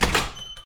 sfx_door_open.ogg